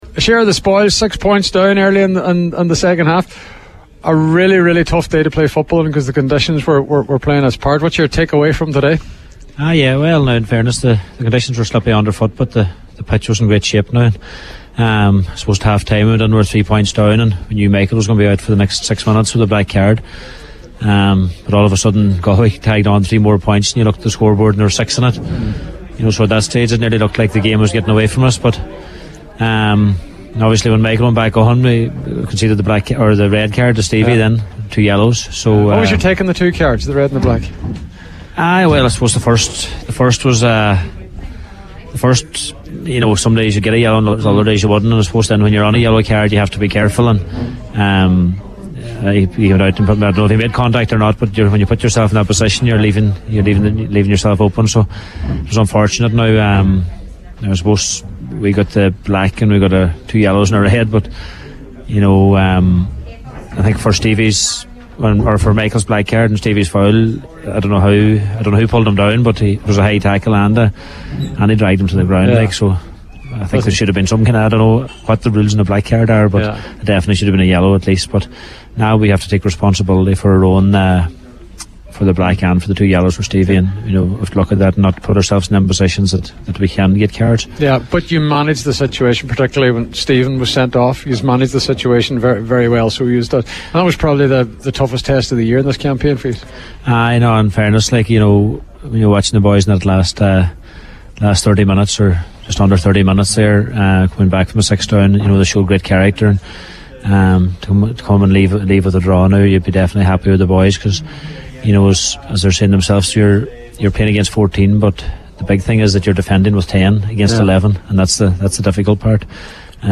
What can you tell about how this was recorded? joined live on Highland Radio Sunday Sport